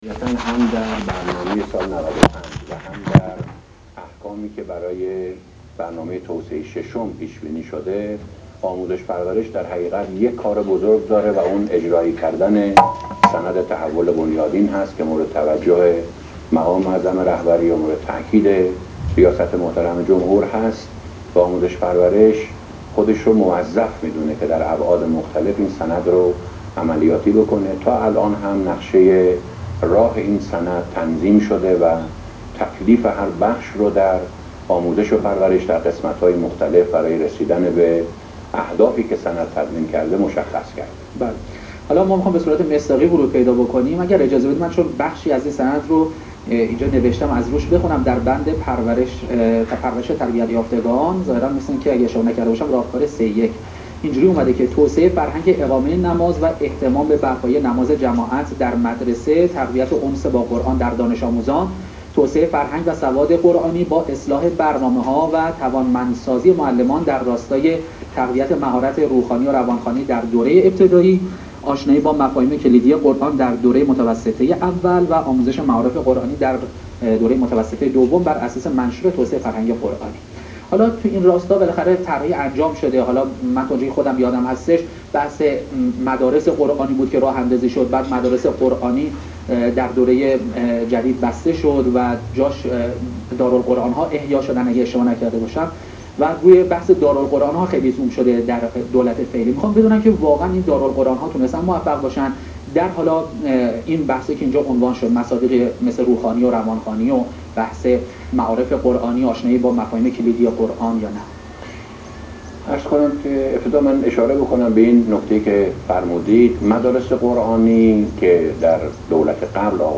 معاون فرهنگی پرورشی وزیر آموزش و پرورش با حضور در برنامه گفتگوی خبری شبکه قرآن سیما به سوالاتی پیرامون وضعیت آموزش دینی و قرآن و زبان عربی و مشکلات دانش آموزان در این زمینه پاسخ داد.